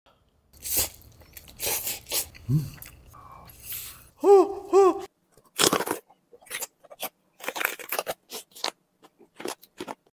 Random Colors food mukbang Korean sound effects free download